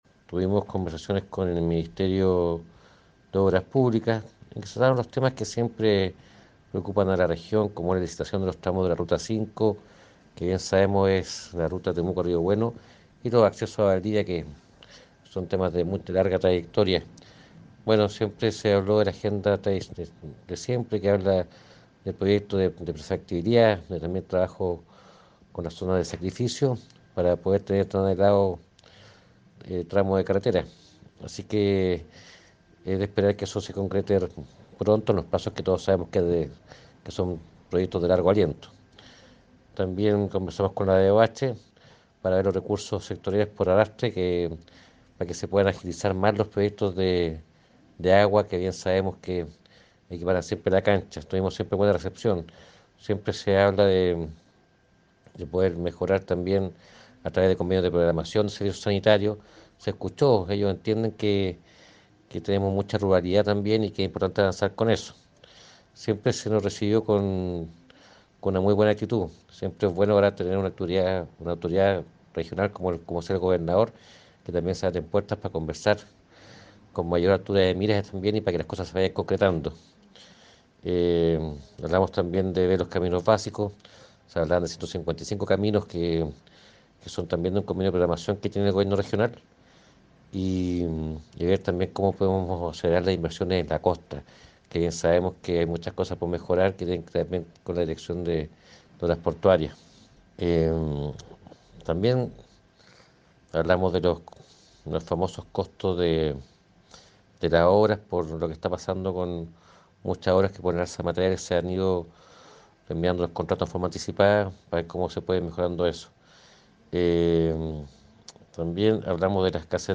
Cuña_Juan-Taladriz_gestiones-ministerios.mp3